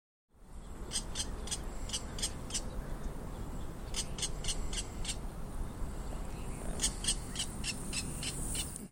Virginia Rail
Rallus limicola